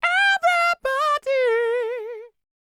DD FALSET013.wav